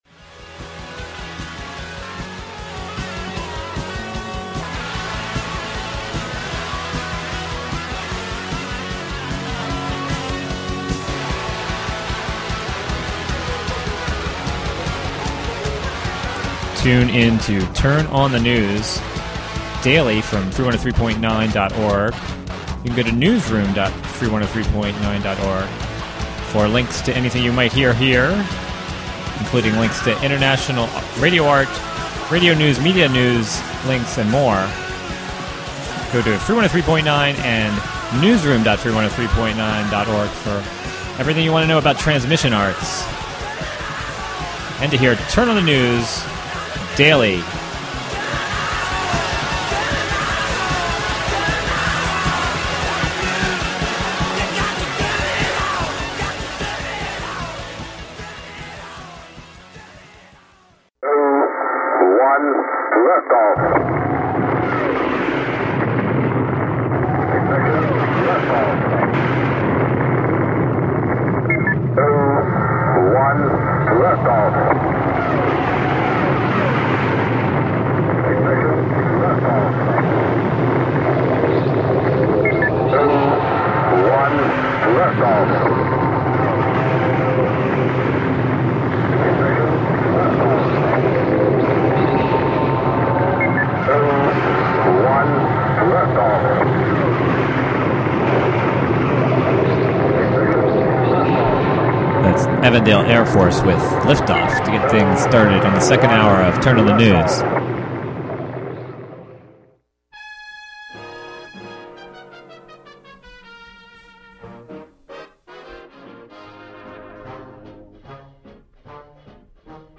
Turn On the News is a daily radio news program fea...